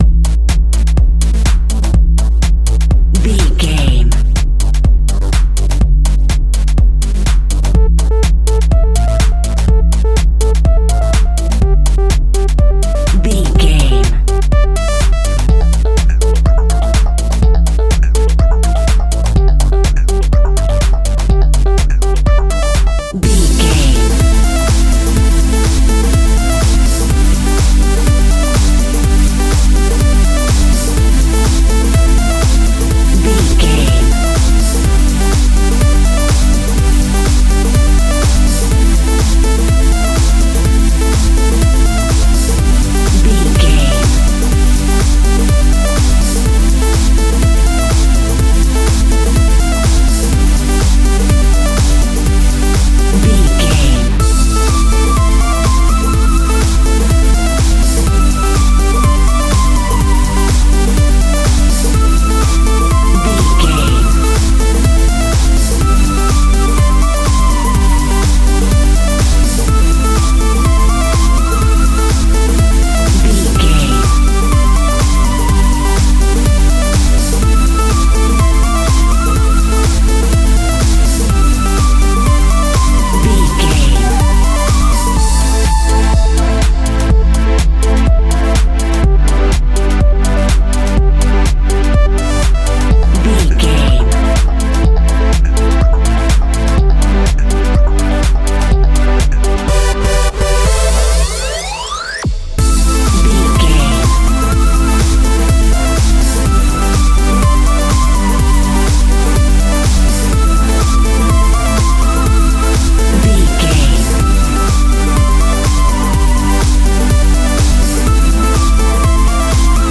Aeolian/Minor
dark
futuristic
driving
energetic
tension
drum machine
synthesiser
electronica
synth leads
techno music
synth bass
synth pad
robotic